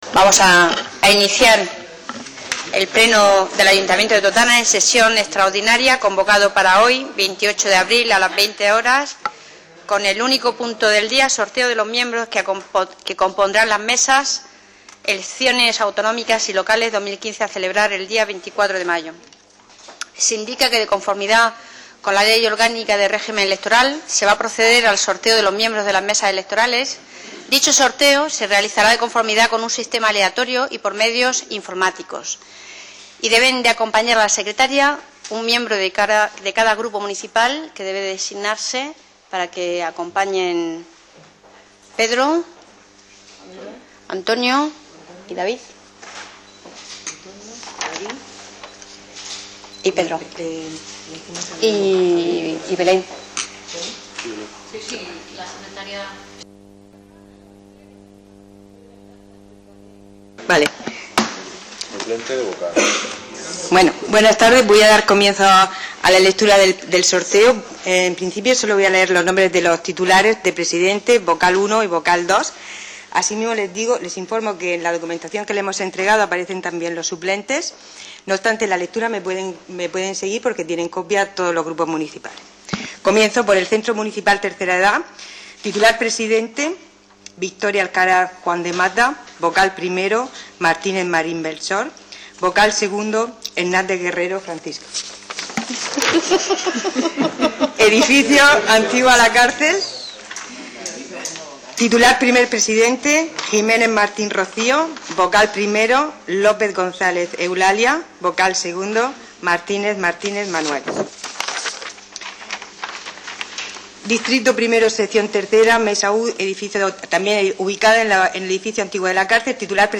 El Ayuntamiento de Totana celebró hoy martes, día 28, un pleno extraordinario para realizar el pertinente sorteo de los miembros que integran las mesas en los colegios electorales en este municipio, para las elecciones municipales y autonómicas que se celebrarán el 24 de mayo.